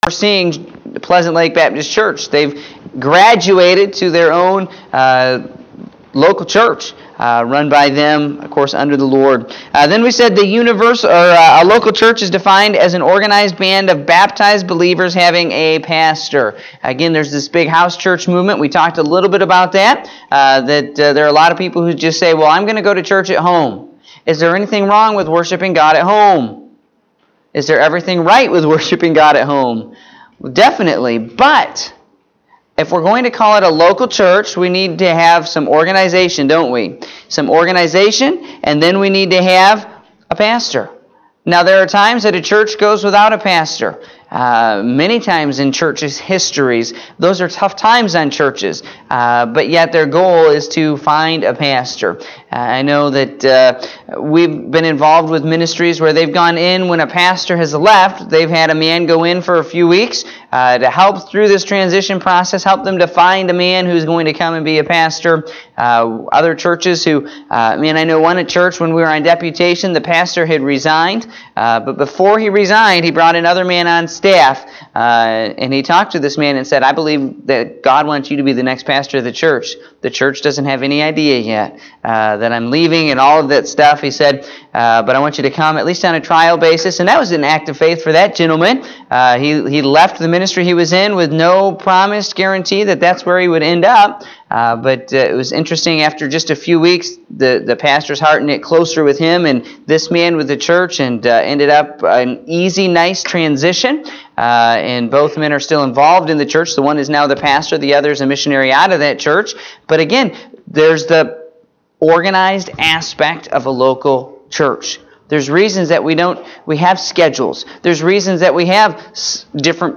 Sunday School (3/11/2018)